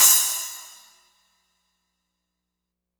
Crashes & Cymbals
Hi_Crsh.wav